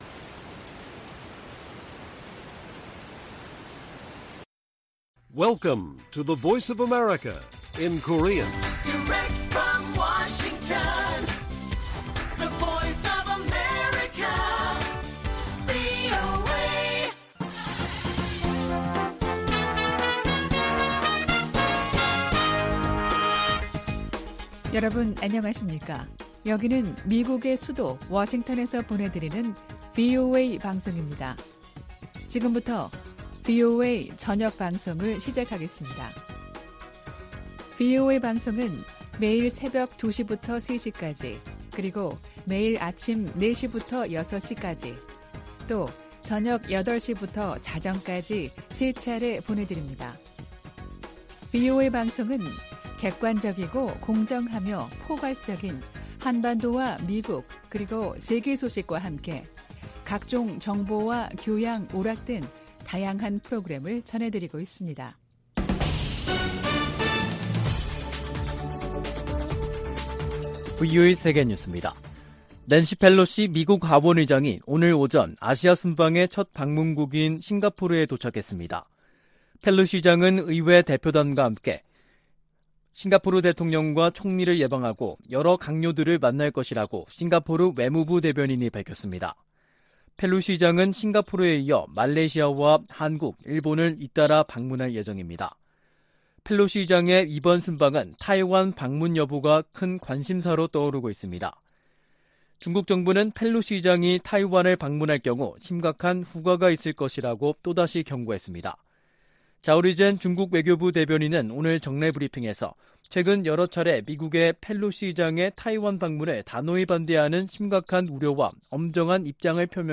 VOA 한국어 간판 뉴스 프로그램 '뉴스 투데이', 2022년 8월 1일 1부 방송입니다. 백악관 국가안보회의(NSC) 고위관리가 미-한 연합훈련과 관련해 준비태세의 중요성을 강조하고, 한반도 상황에 맞게 훈련을 조정하고 있다고 밝혔습니다. 미 국방부는 중국의 사드 3불 유지 요구와 관련해 한국에 대한 사드 배치는 두 나라의 합의에 따라 결정될 것이라는 입장을 밝혔습니다. 밥 메넨데즈 미 상원 외교위원장이 '쿼드'에 한국을 포함해야 한다고 말했습니다.